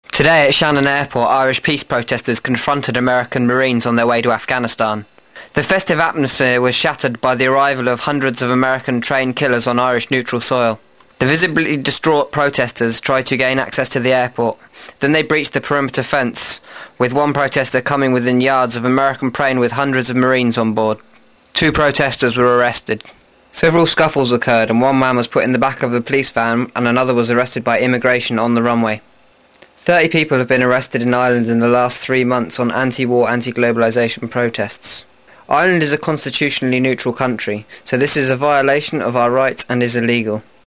Audio report from SHANNON anti WAR protest IRELAND